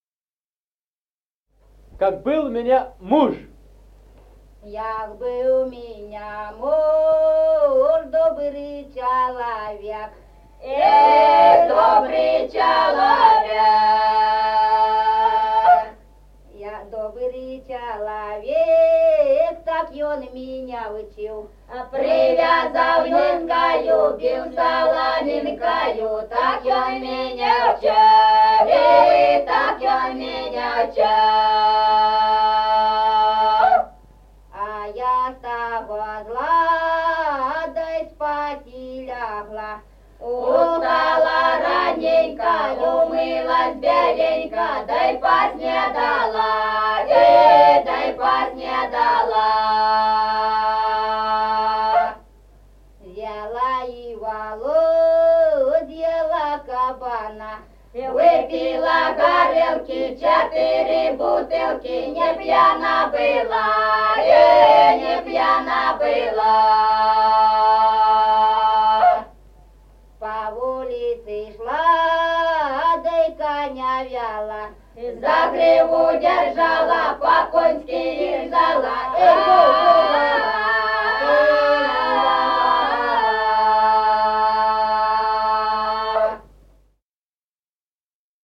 Музыкальный фольклор села Мишковка «Як быв ў меня муж», лирическая.